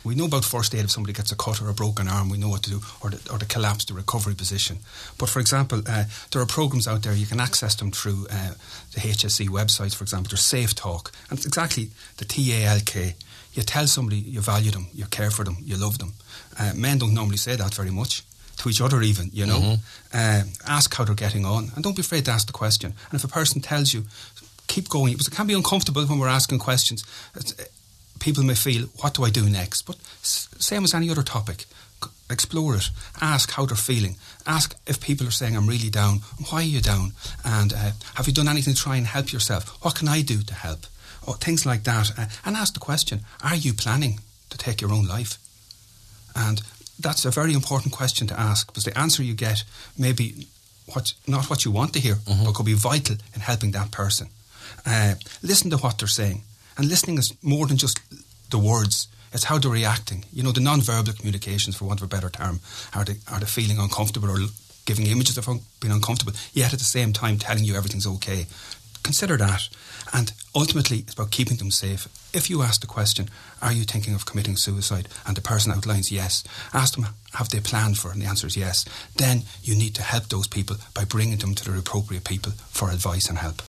on this morning’s Nine ’til Noon Show.